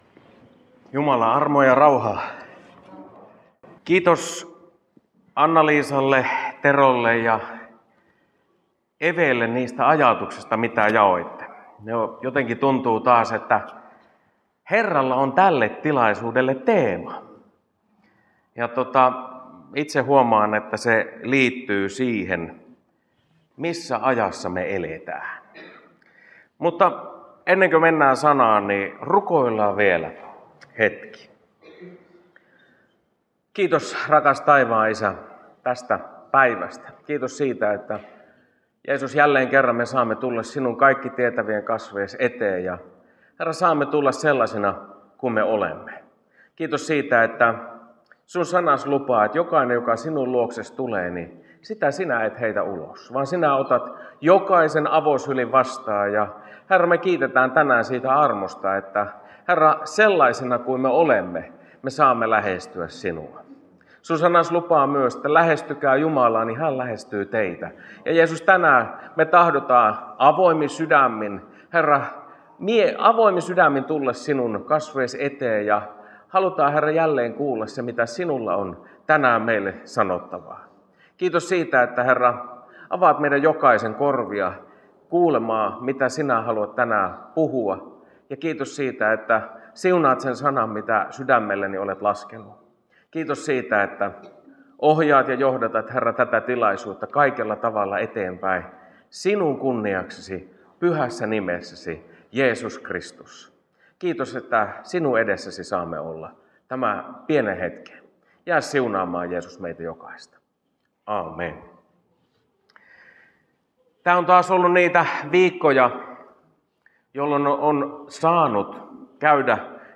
Kokkolan Baptistiseurakunta 8.3.2026